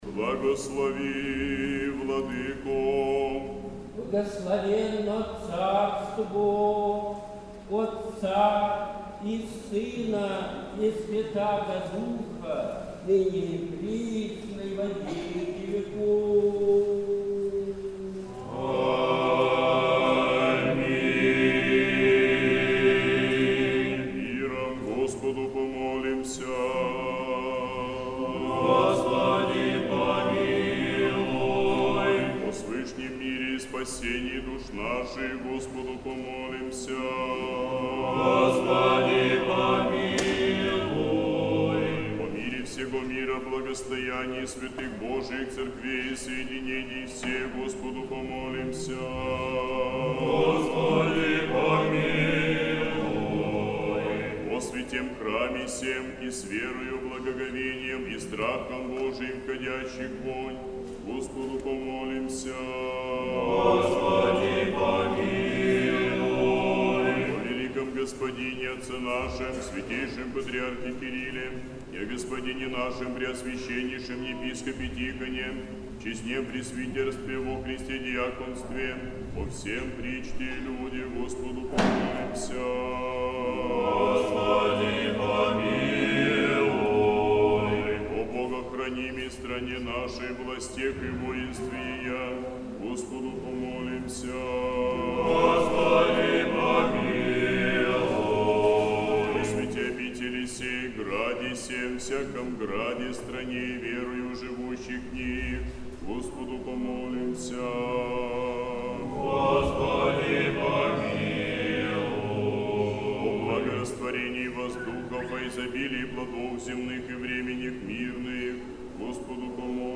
Сретенский монастырь. Божественная литургия. Хор Сретенской Духовной Семинарии.
Божественная литургия в Сретенском монастыре в Неделю 10-ю по Пятидесятнице, в праздник Успения Божией Матери